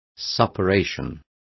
Complete with pronunciation of the translation of suppurations.